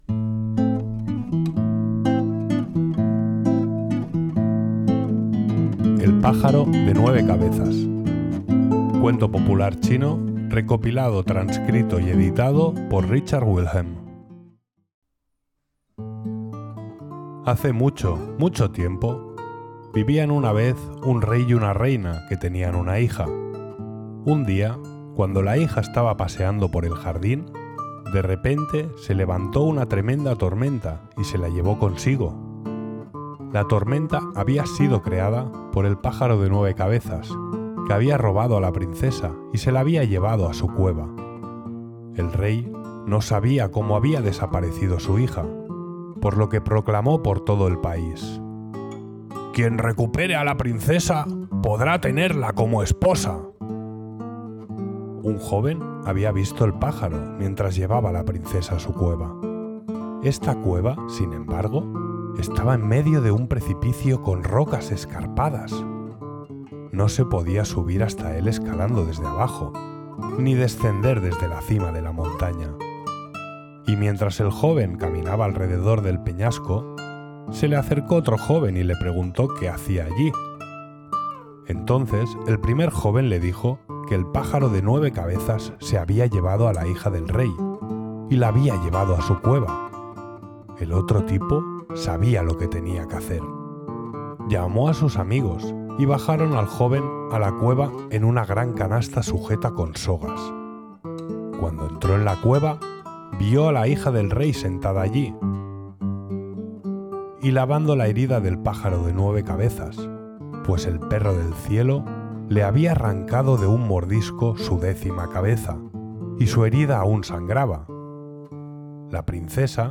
Cuento popular chino, recopilado, trascrito y editado por Richard Wilhelm (1873-1930)
Música interpretada a la guitarra
Arreglos de música asiática para guitarra